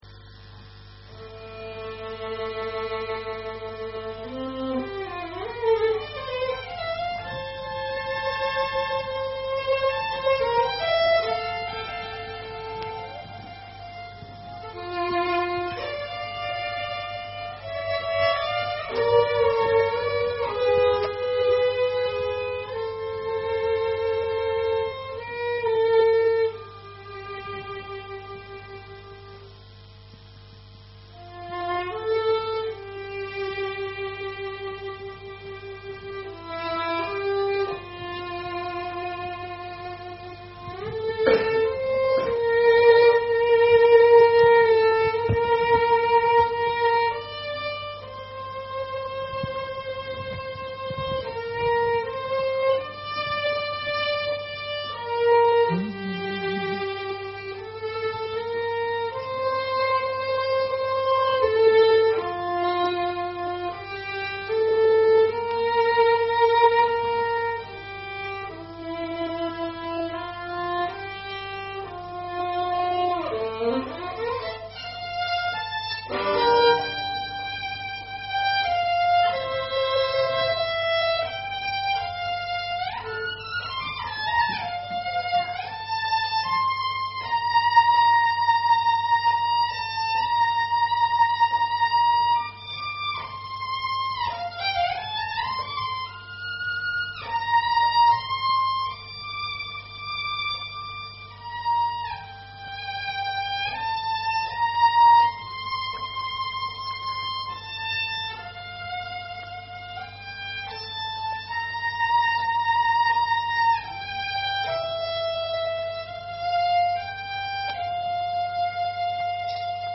Violin Solo 10th November 2012